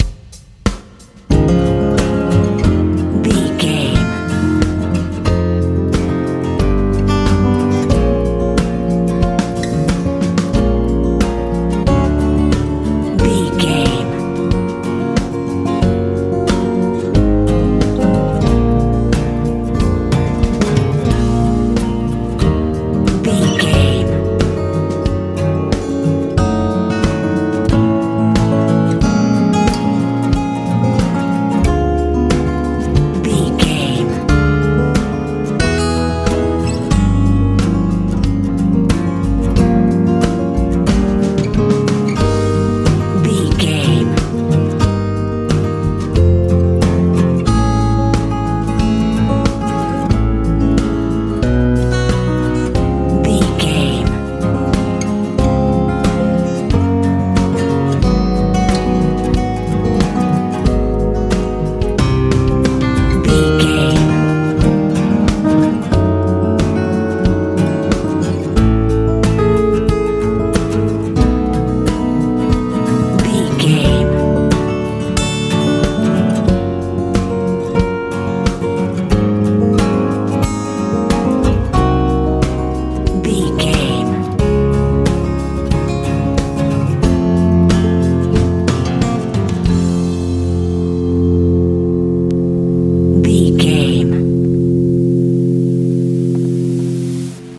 easy listening
Ionian/Major
light
mellow
electric piano
acoustic guitar
bass guitar
drums